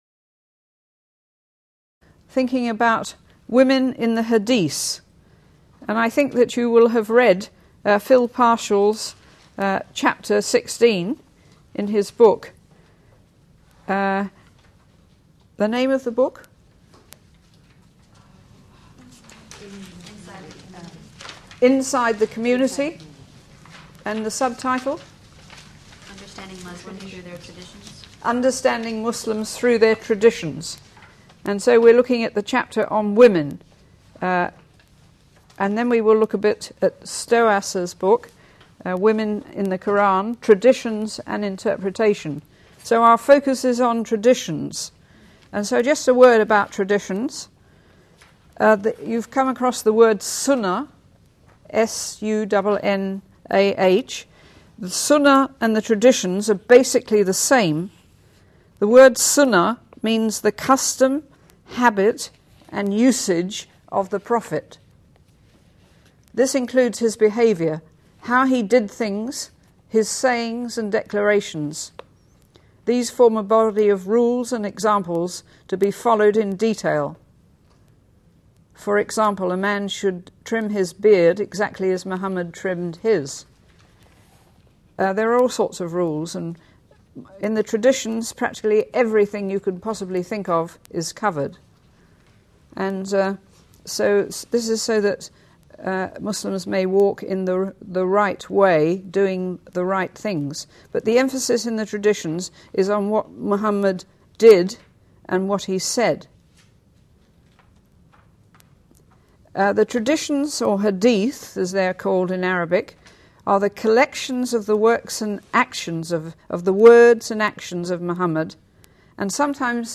These lectures were given at Columbia International University in partnership with the Zwemer Center for Muslim Studies.